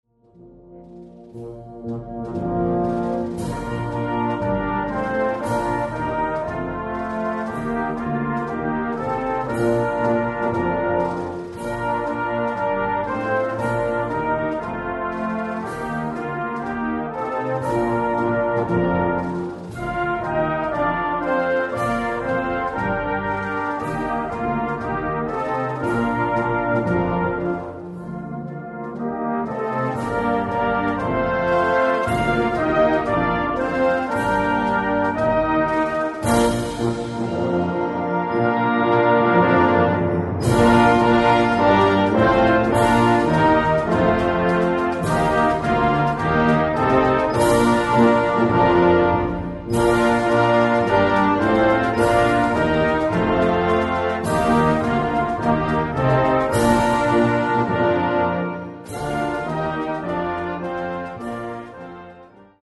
Genre: Brass Ensemble
for brass ensemble
Timpani
Percussion 2 (bells)